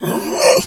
bear_pain_hurt_groan_02.wav